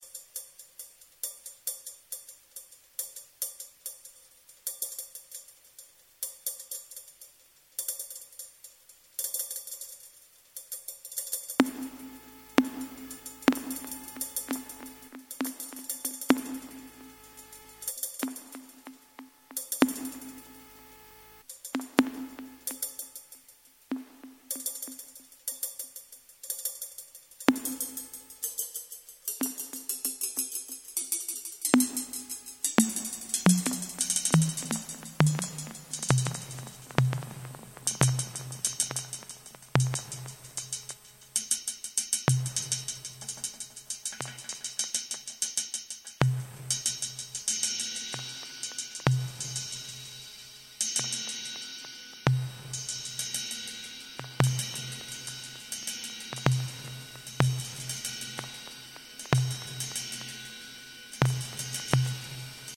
一般的なパターンとは一味も二味もズラしてくるリズム、そしてザラついた質感の妙。